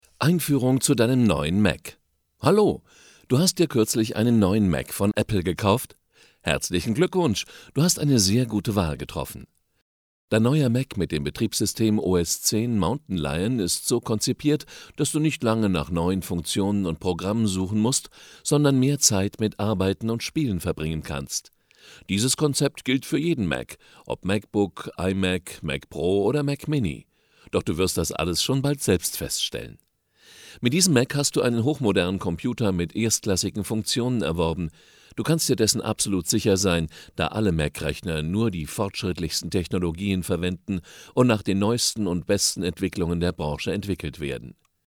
Sprechprobe: eLearning (Muttersprache):
German voice over artist with more than 30 years of experience.